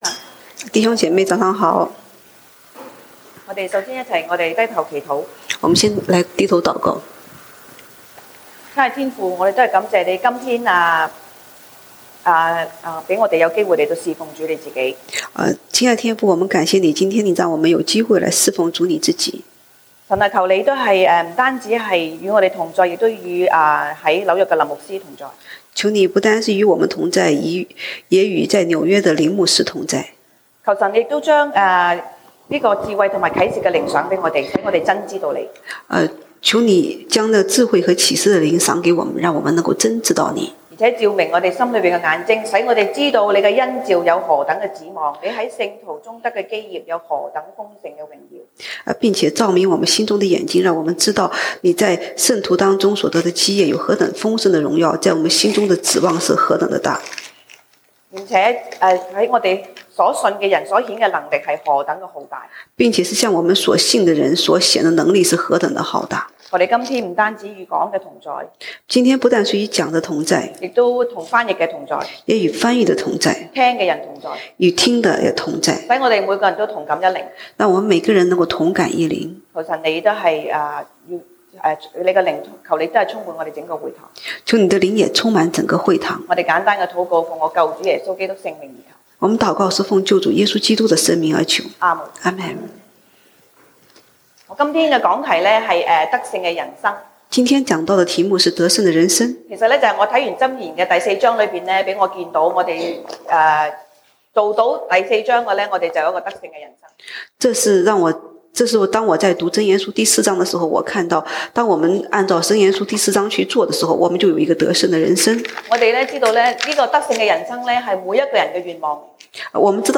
西堂證道(粵語/國語) Sunday Service Chinese: 箴言 Proverbs 4:1-27